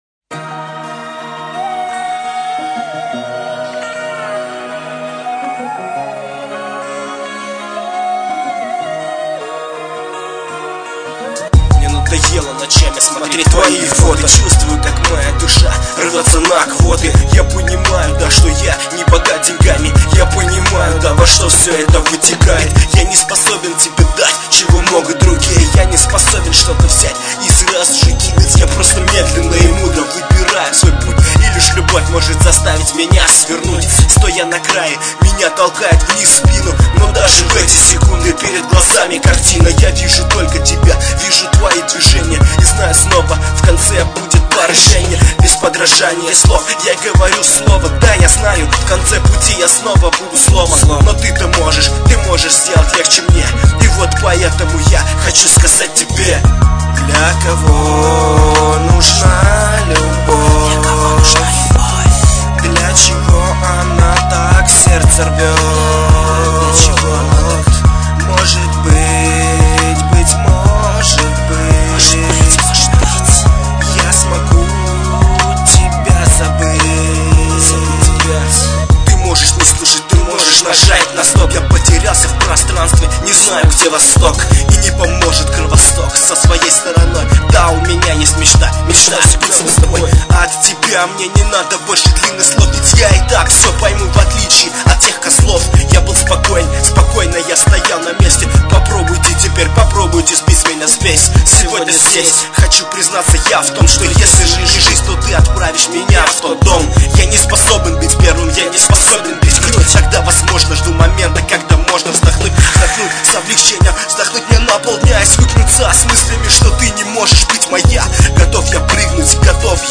next 08 Rap Это не Adult файл Файл проверен от 03.03.2009